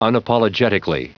Prononciation du mot unapologetically en anglais (fichier audio)
Prononciation du mot : unapologetically